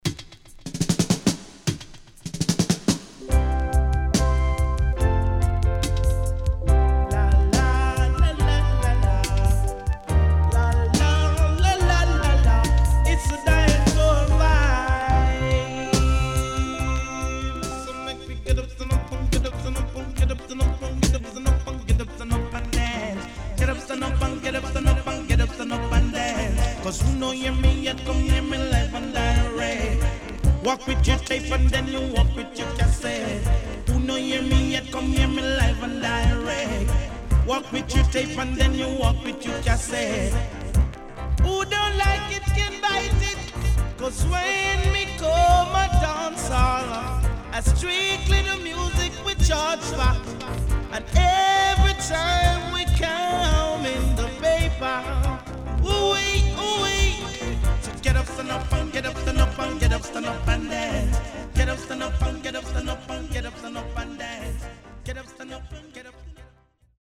HOME > DISCO45 [DANCEHALL]
SIDE A:所々チリノイズがあり、少しプチノイズ入ります。